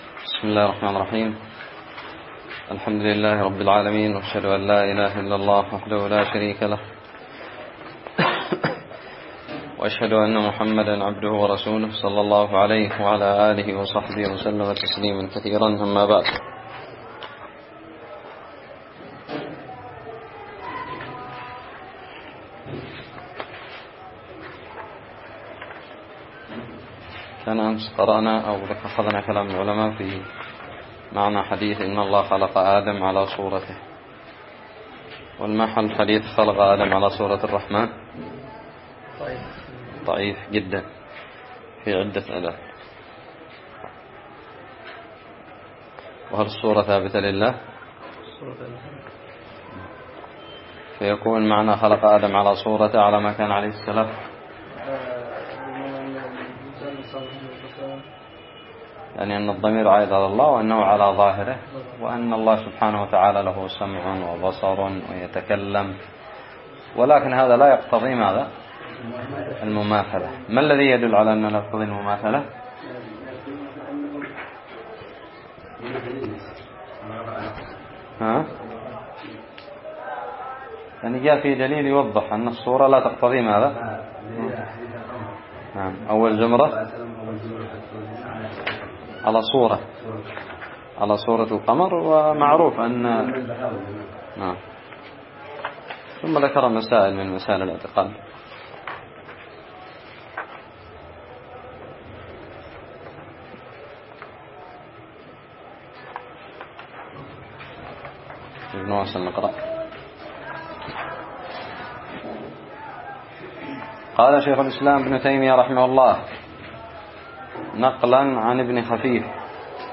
الدرس السادس عشر من شرح متن الحموية
ألقيت بدار الحديث السلفية للعلوم الشرعية بالضالع